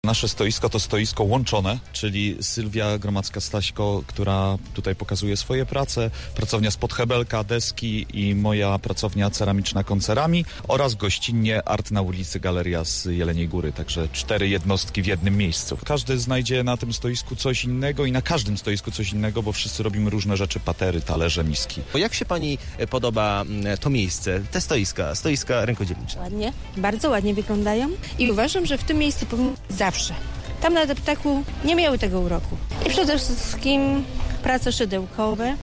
Na placu Powstańców Wielkopolskich ulokowali się rękodzielnicy. Ich wyroby cieszą się uznaniem wśród gości: https